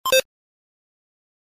Song: SFX Item